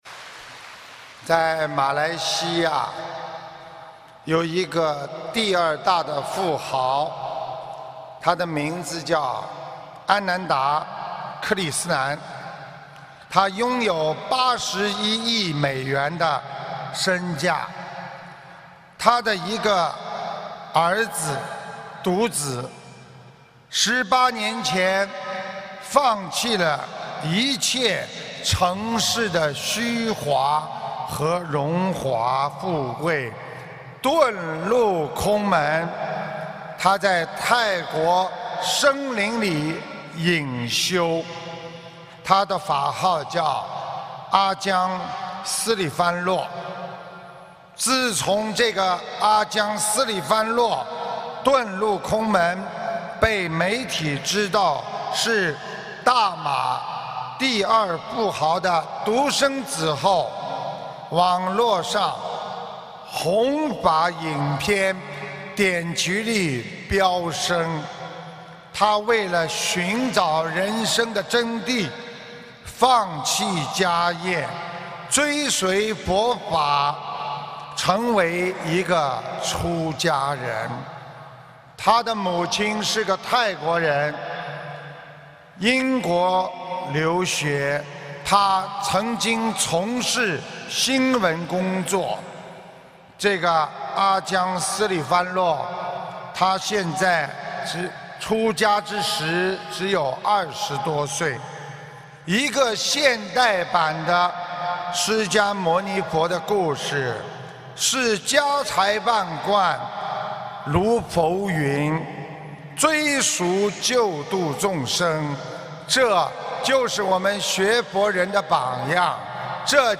视频：〔音频〕14年3月2日 马来西亚吉隆坡 开示：马来西亚第二大富豪：安南达克里斯南